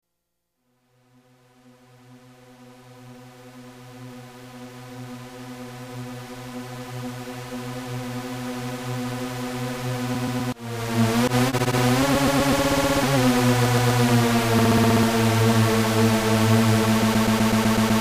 标签： 120 bpm Dubstep Loops Fx Loops 3.03 MB wav Key : Unknown
声道立体声